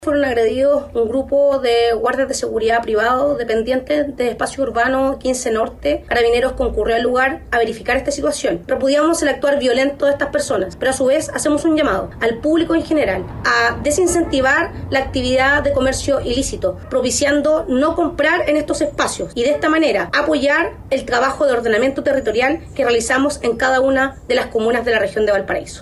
La seremi interina de Seguridad Pública en Valparaíso, Paula Gutiérrez, hizo un llamado a la ciudadanía a no incentivar el comercio ilegal a nivel comunal y regional.